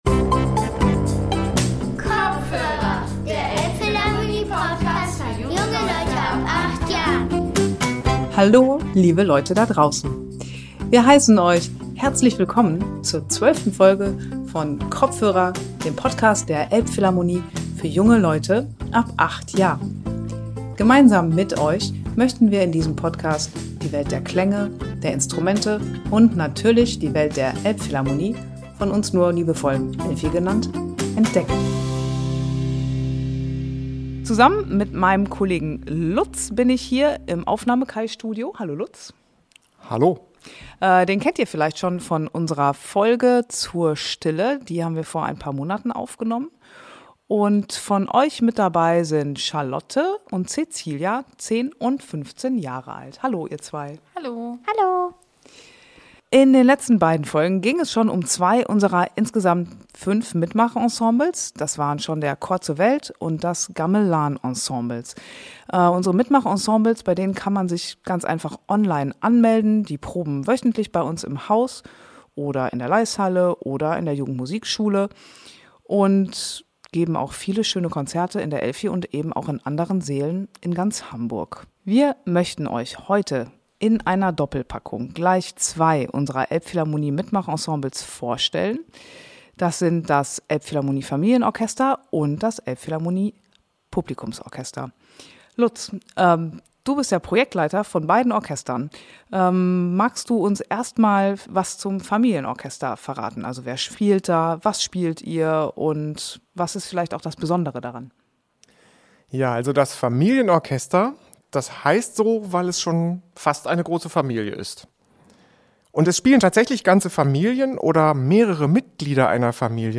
Beim Kopfhörer-Quiz seid ihr dran: Erkennt ihr die verschiedenen Instrumente an ihrem Klang?